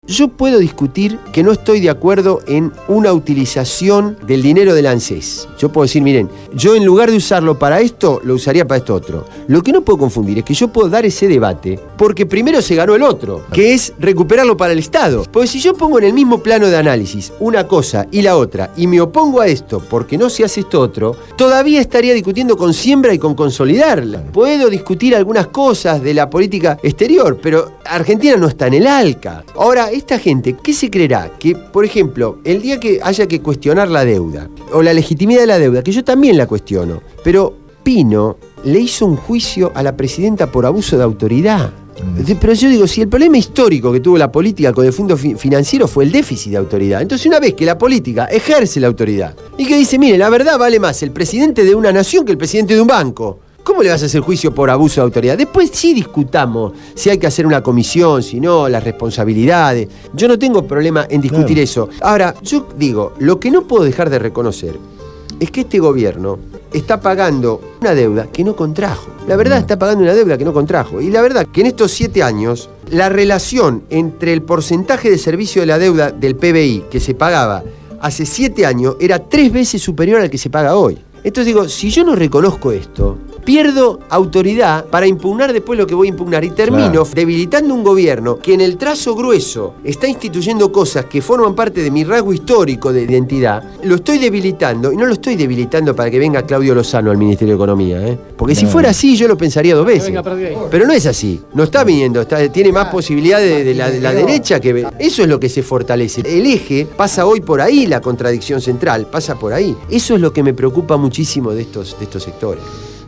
Carlos Raimundi, diputado del bloque Solidaridad e Igualdad (SI) estuvo en el piso de Radio Gráfica durante la emisión del programa «Cambio y Futuro» (Jueves, de 20 a 22 hs).